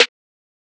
REDD Snare (15).wav